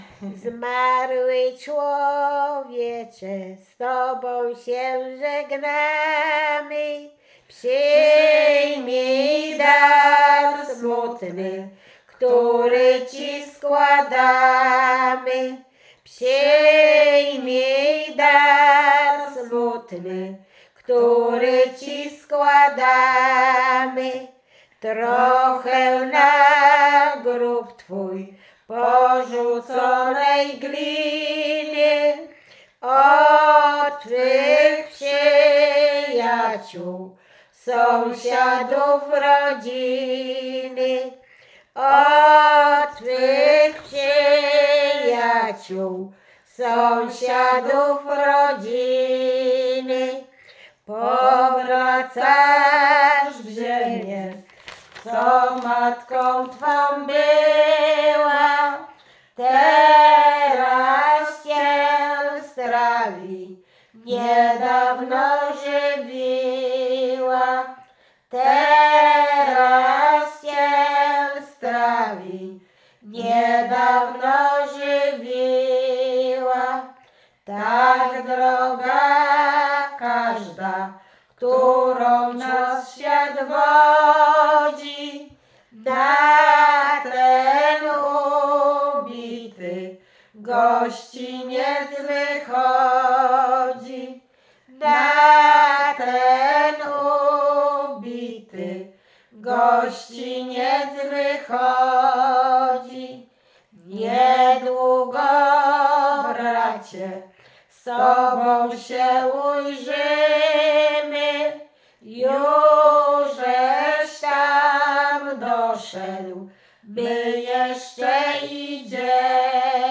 Łęczyckie
Pogrzebowa
pogrzebowe nabożne katolickie do grobu